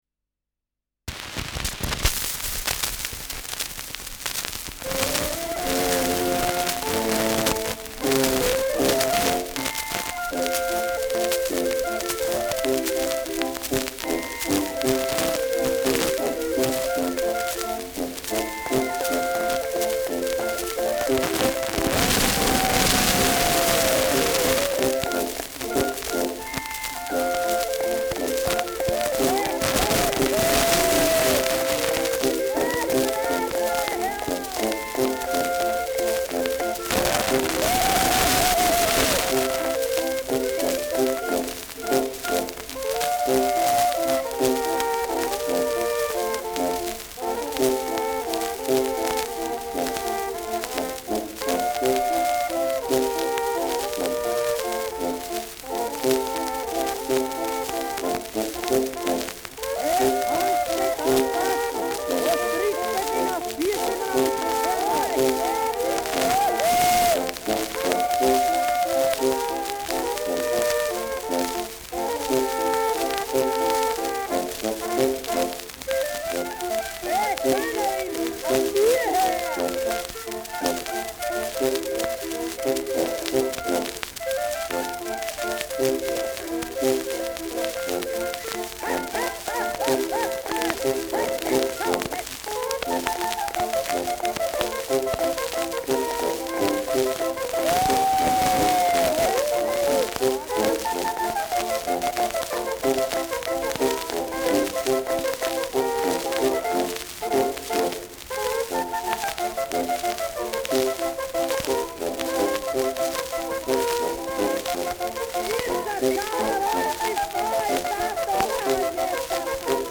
Schellackplatte
Starkes Grundknistern : Gelegentlich starkes Zischen : gelegentlich leichtes bis stärkeres Knacken : Nadelgeräusch gegen Ende
Bauernkapelle Salzburger Alpinia (Interpretation)
Mit Rufen, Juhu und Indianergeheul.